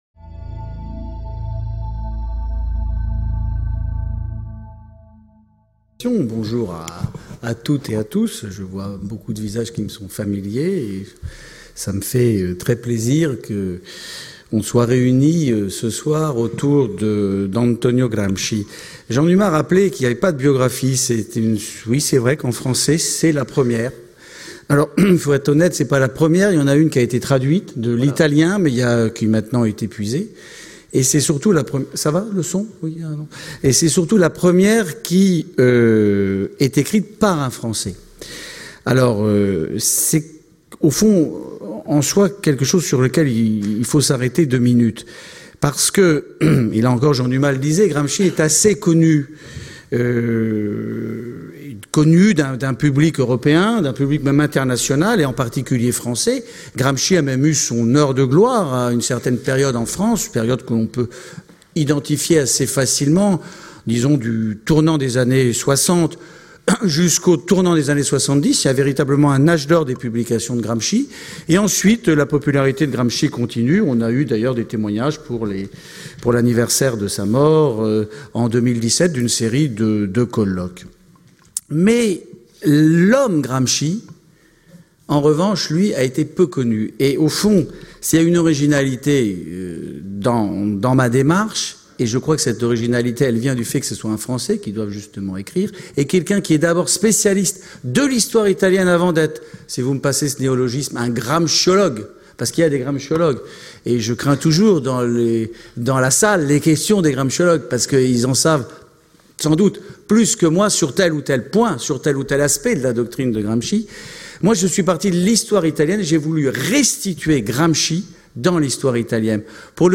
Telles sont les ambitions de cette conférence.